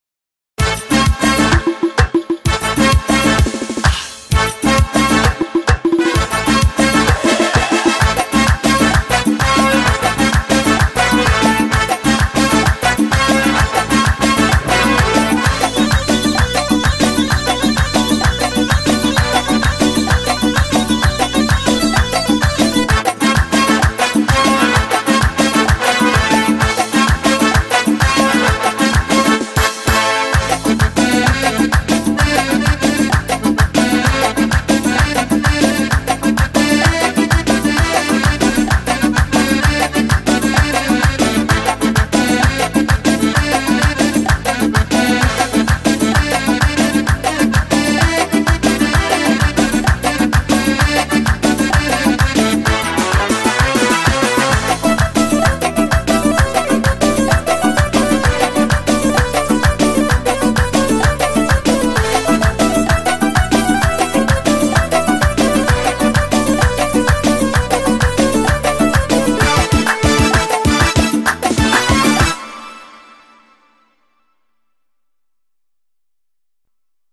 ارکستری